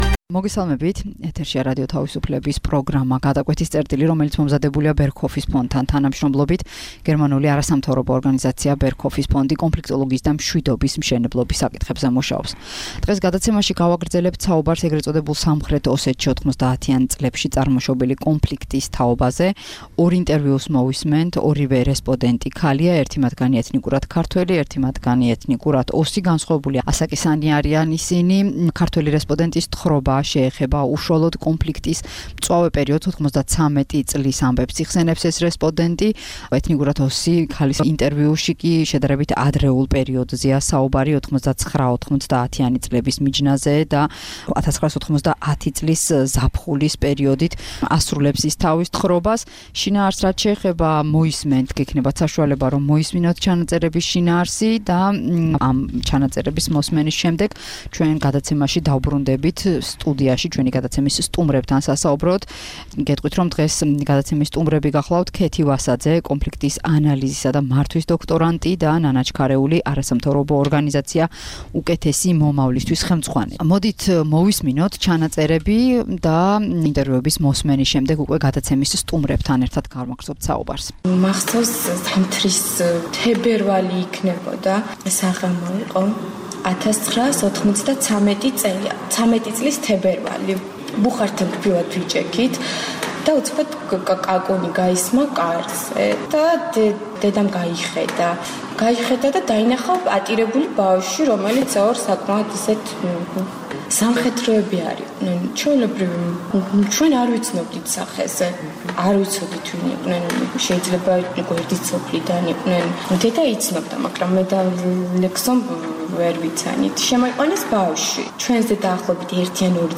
გადაცემაში ორი რესპონდენტი ქალი იხსენებს ე. წ. სამხრეთ ოსეთში 90-იან წლებში მიმდინარე კონფლიქტის სხვადასხვა პერიოდს.